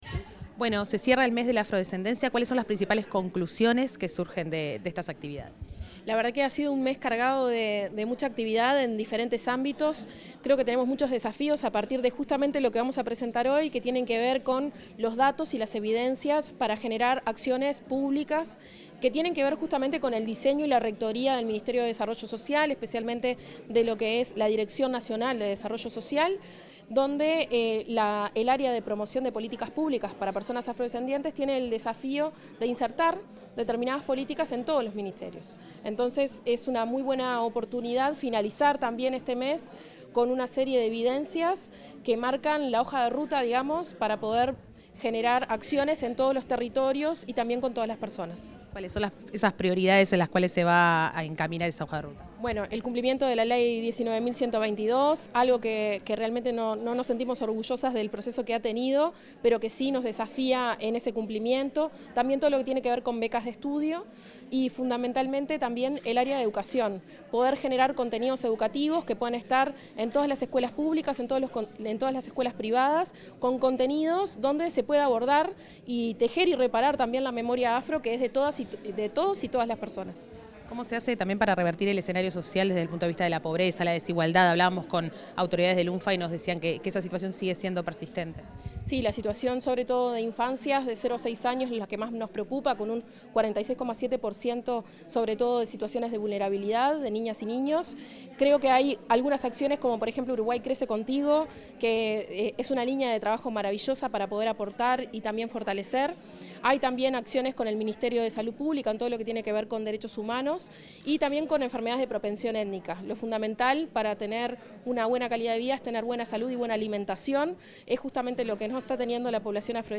Declaraciones de la directora de Promoción de Políticas Públicas para Afrodescendientes, Leticia Rodríguez Taborda
Declaraciones de la directora de Promoción de Políticas Públicas para Afrodescendientes, Leticia Rodríguez Taborda 31/07/2025 Compartir Facebook X Copiar enlace WhatsApp LinkedIn Al finalizar la jornada de cierre del Mes de la Afrodescendencia, la directora de la División de Promoción de Políticas Públicas para Afrodescendientes, Leticia Rodríguez Taborda, realizó declaraciones.